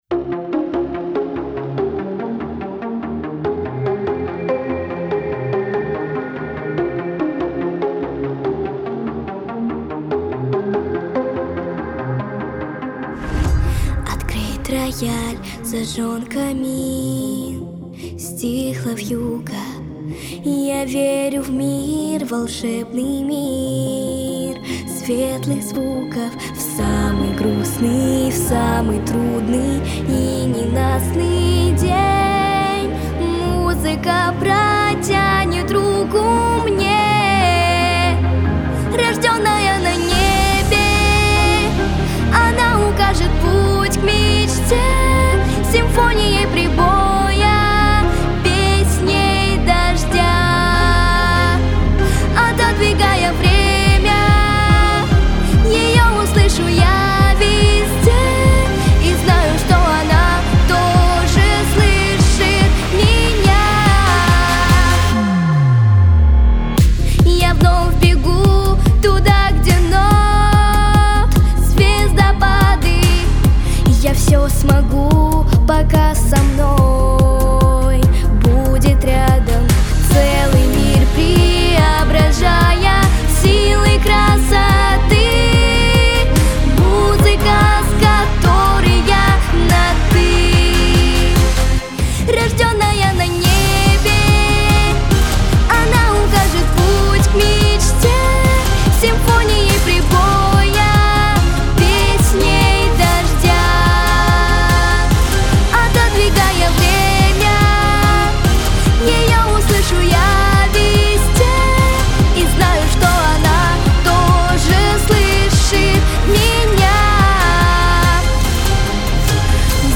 Яркая современная песня про музыку.
Характер песни: позитивный.
Темп песни: средний.
Диапазон: Соль малой октавы - До второй октавы.